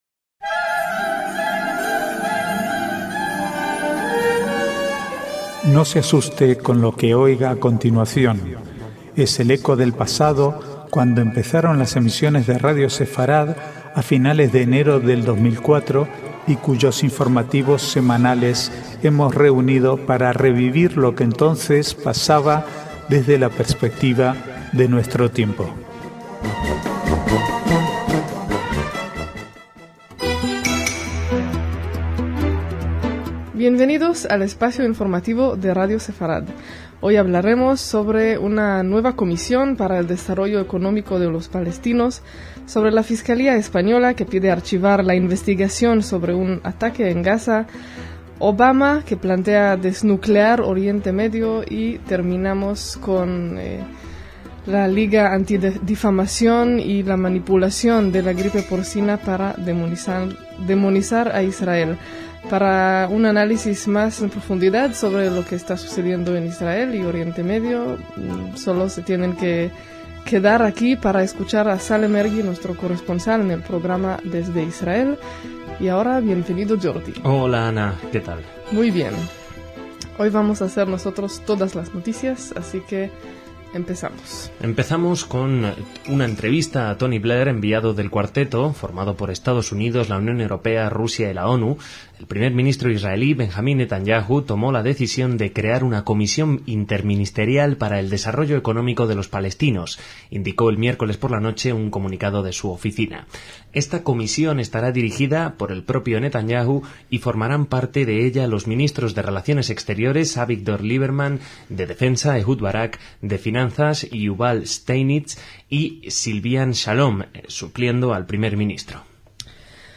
Archivo de noticias del 8 al 13/5/2009